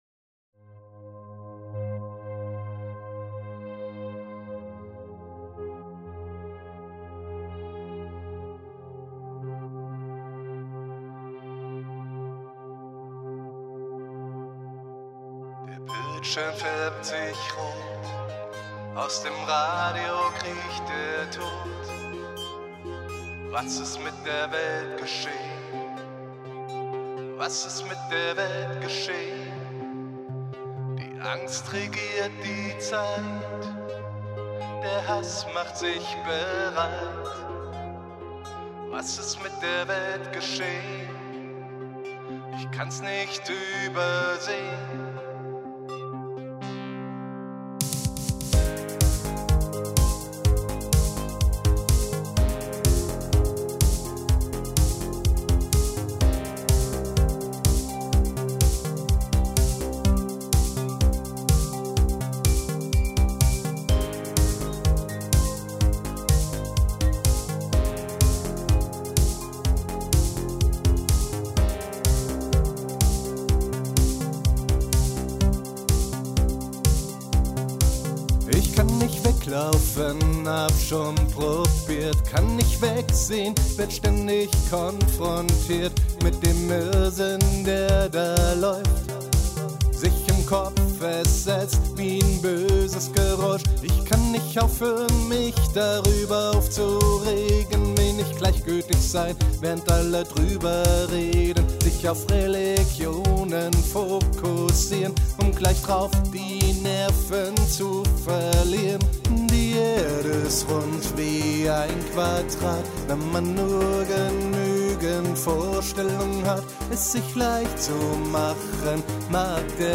Ein neuer Song von mir benötigt Feedback, insbesondere für den Mix. Das Lied selbst würde ich in die Kategorie elektronische Popmusik einordnen, wobei ich mich mit so etwas immer schwer tue. Gesanglich, glaube ich, fehlt hier und da ein wenig Luft, es kommt mir zu trocken rüber.